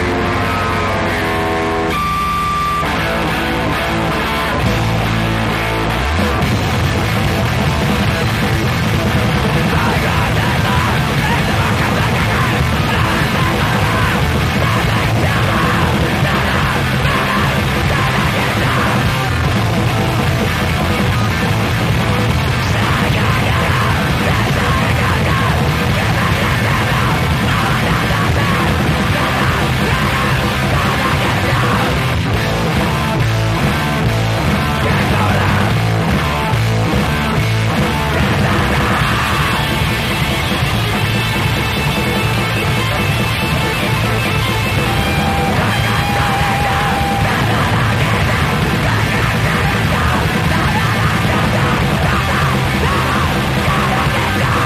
625 THRASHCORE
BASS
DRUMS
GUITAR
VOCALS